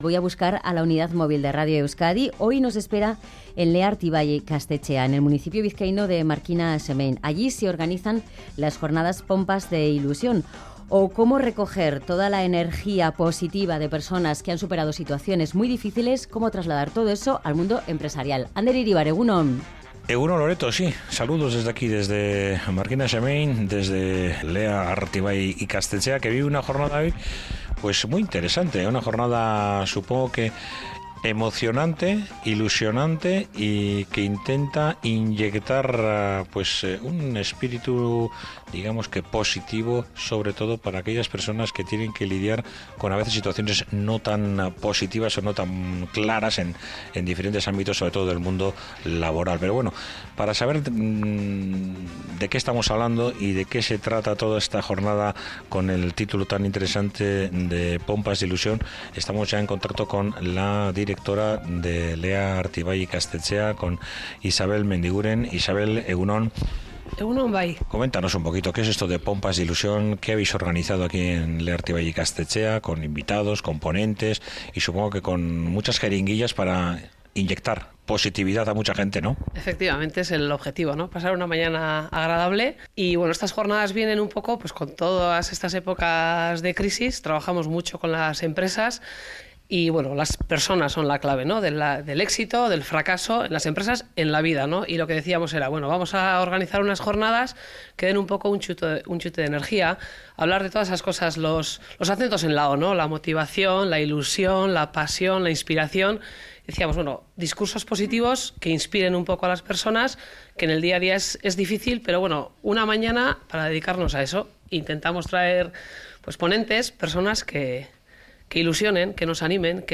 Con ellos ha estado la Unidad Móvil de Radio Euskadi.